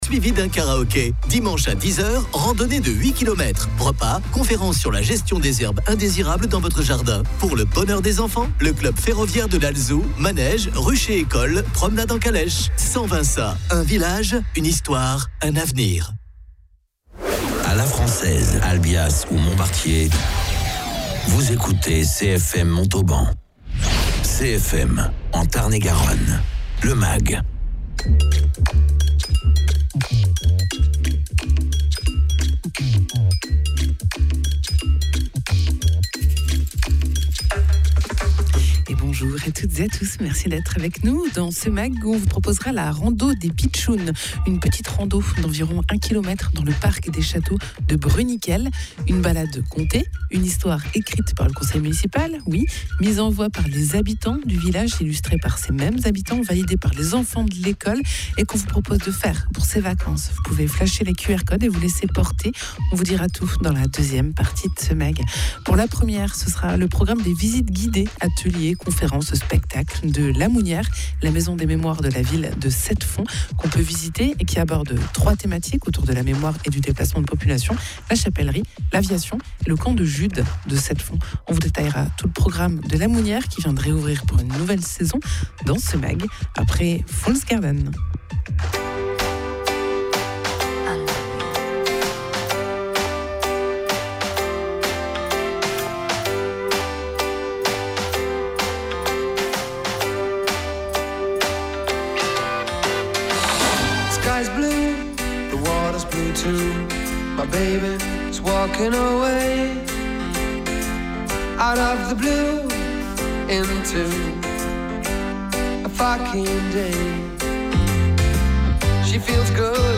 Invité(s) : Sébastien Basse, adjoint à la mairie de Bruniquel